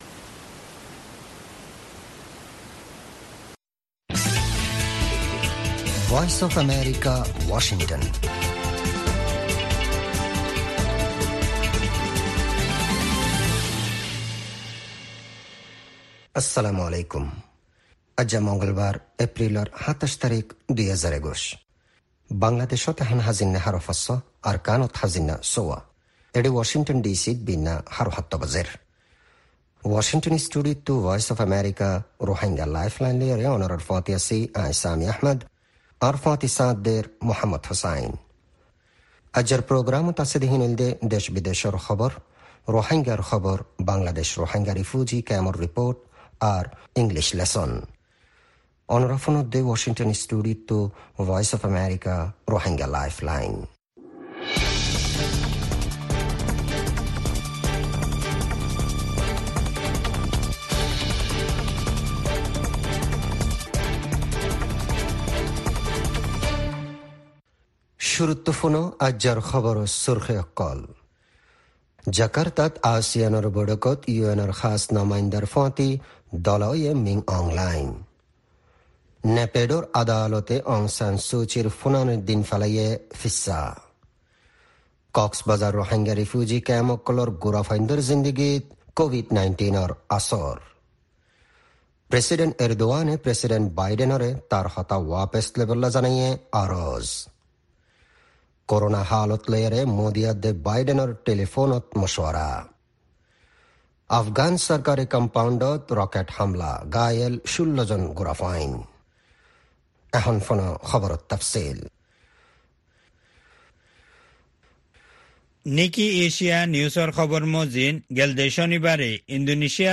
Rohingya Broadcast
Rohingya “Lifeline” radio
News Headlines